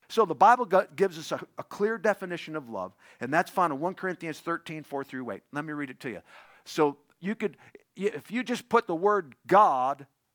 If it matters, this is for Sunday service.
Here I have split the track at approximately the time of the sample rate change, then changed the sample rate of the latter section to 48000, then mixed down the two parts into this continuous clip:
As you can hear, there is no evidence of the sample rate “drifting”, it’s a clean switch from 44100 to 48000.